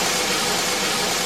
soft-sliderslide.mp3